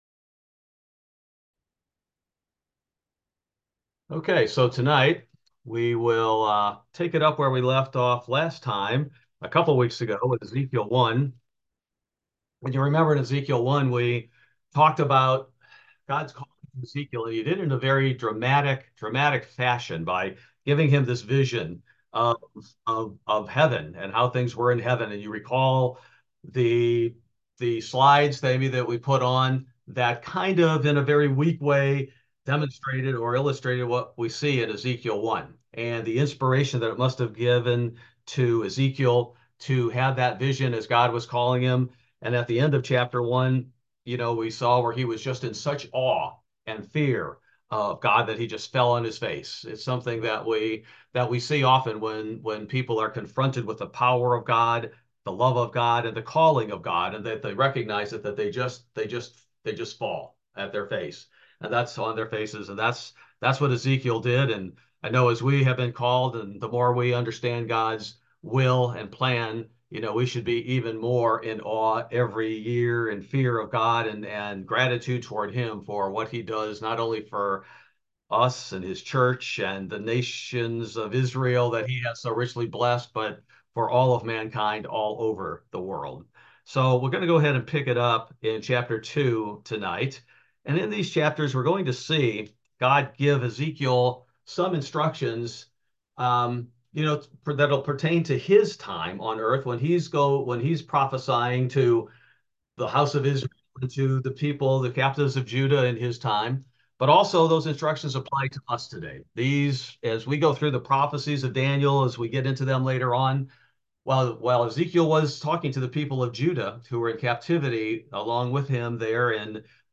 Bible Study: April 3, 2024